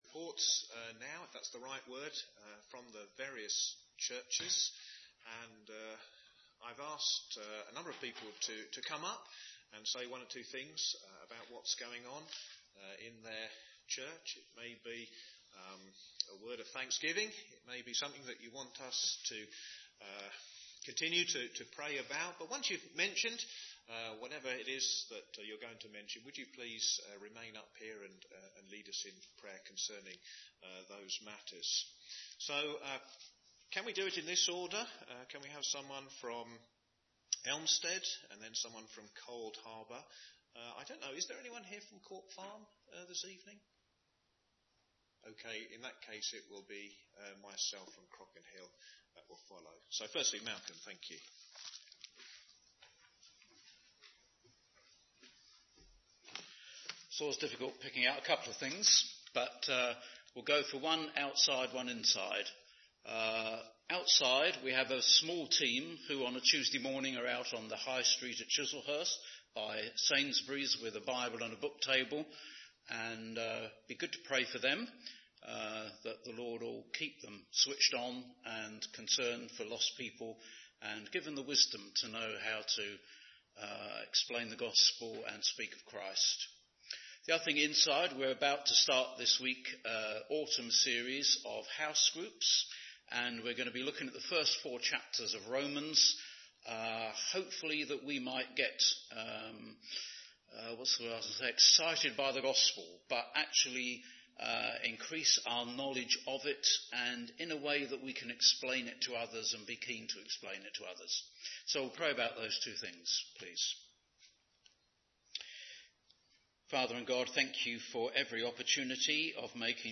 Five Churches Service